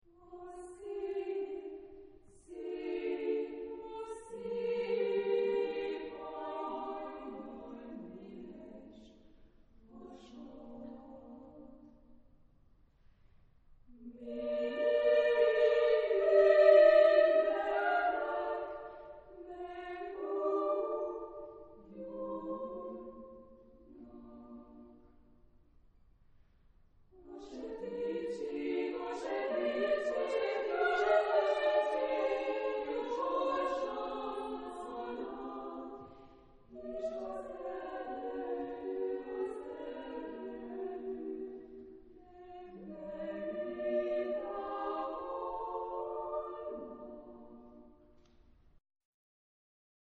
Género/Estilo/Forma: Profano ; Lírica ; Coro
Tipo de formación coral: SMA  (3 voces Coro femenino )
Tonalidad : centros tonales